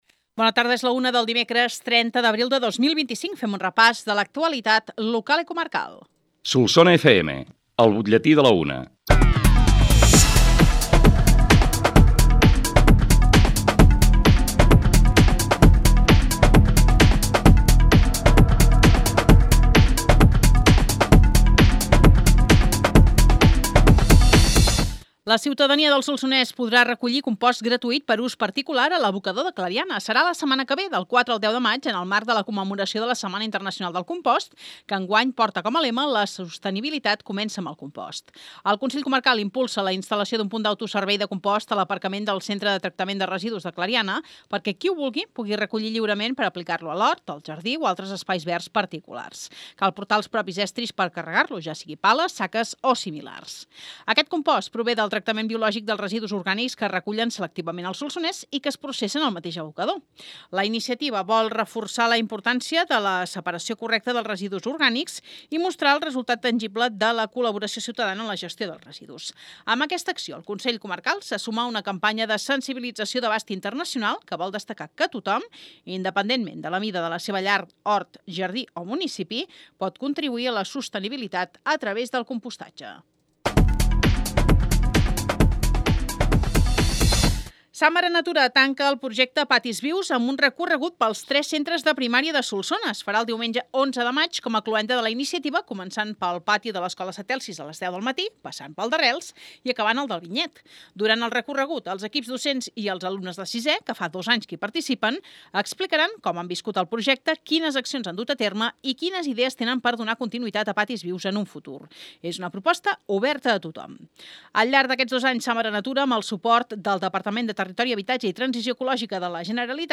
L’ÚLTIM BUTLLETÍ